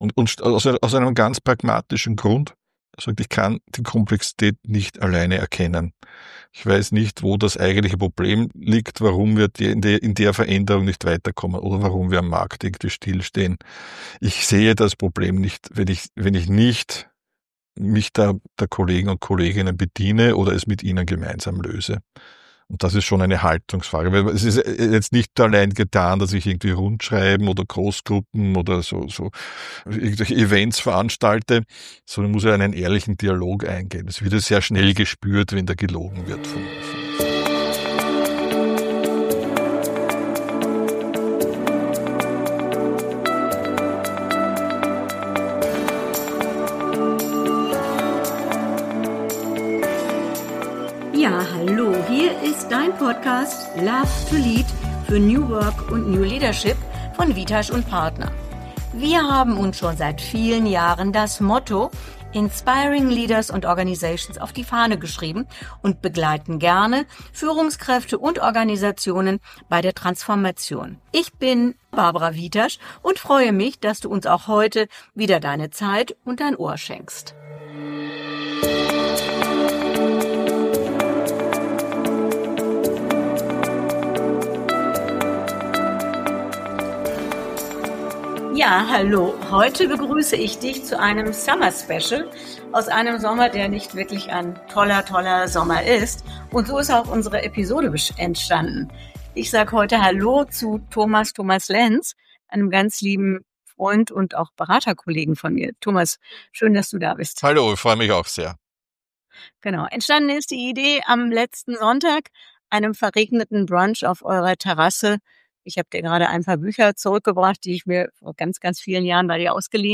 Was passiert, wenn zwei erfahrene Change-/Transformations-Berater beim Sommer-Brunch zusammensitzen? Eine neue Podcast-Episode entsteht – spontan, ehrlich und voller Praxis!